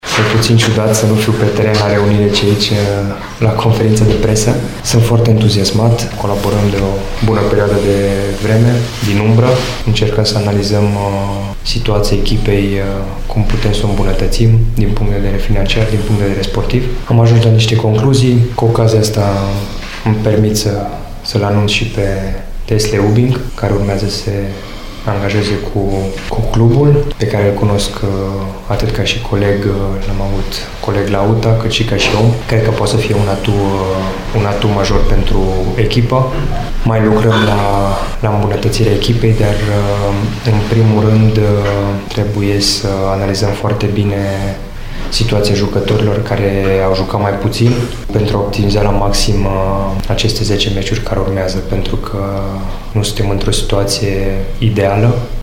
La conferința de presă organizată astăzi, Keșeru a vorbit despre noua sa postură și a anunțat un prim transfer de răsunet, cel al fostului său coleg de la UTA, Desley Ubbink: